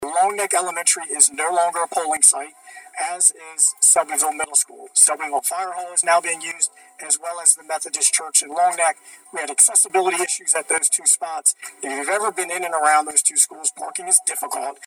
The meeting was held at Indian River High School.